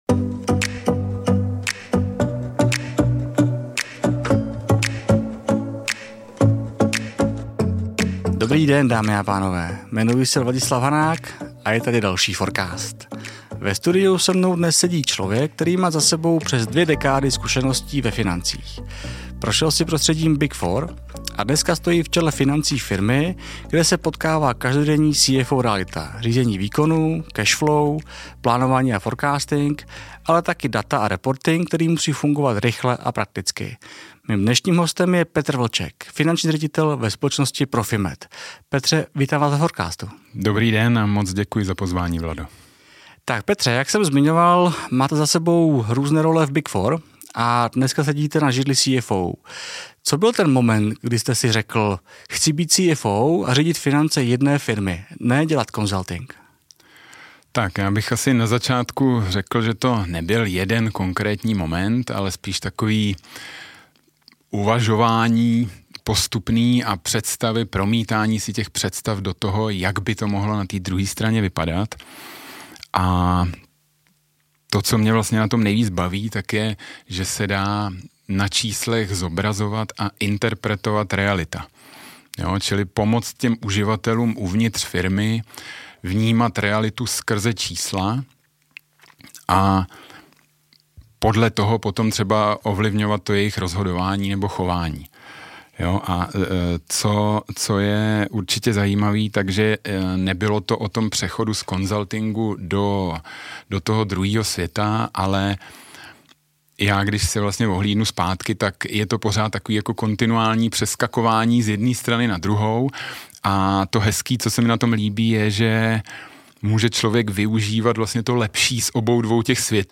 V rozhovoru jsme se bavili také o velmi praktických CFO tématech: o řízení financí napříč regionem, o práci s daty v reportingových nástrojích, o roli motivace lidí za čísly nebo o tom, kdy je Excel stále skvělý nástroj a kdy už se z něj stává riziko.